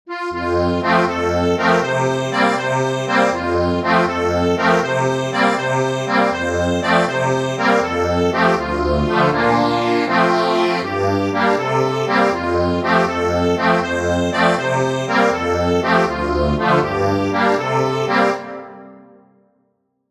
Instrument:  Accordion